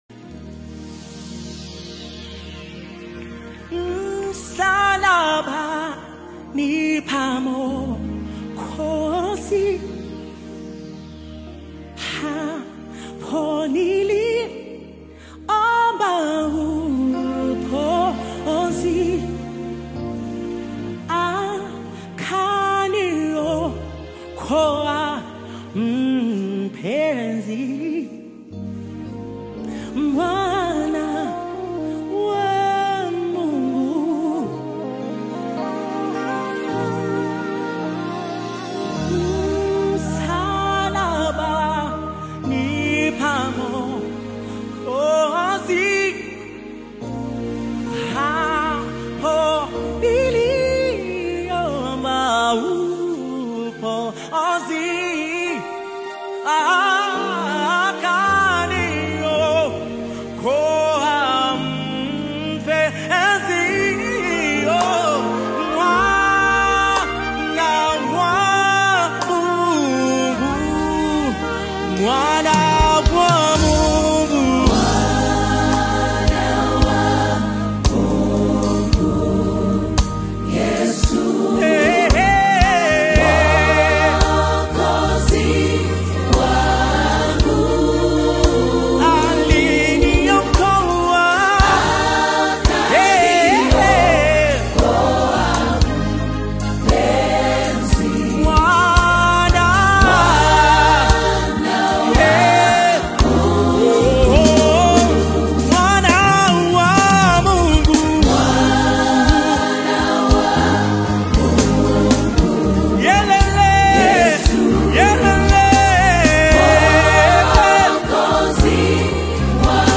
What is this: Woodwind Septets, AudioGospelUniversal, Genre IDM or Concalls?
AudioGospelUniversal